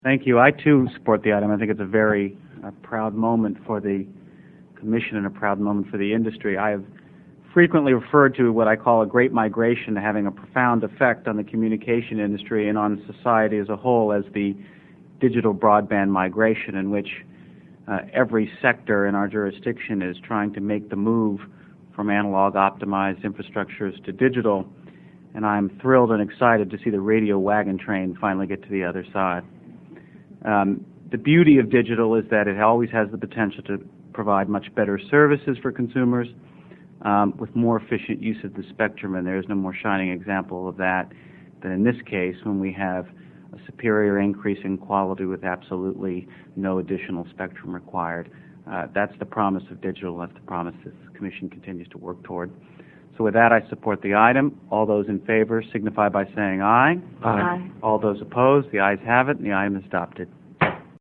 Note: All audio is in 48kbps/44KHz mono MP3 format.
FCC Chairman Michael Powell (1:00, 358K)
Deaf to its flaws, Powell turned in the second-shortest comments (and his clip above includes the actual Commission vote).